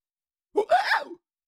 Cartoon Little Monster, Voice, Hiccup 3 Sound Effect Download | Gfx Sounds
Cartoon-little-monster-voice-hiccup-3.mp3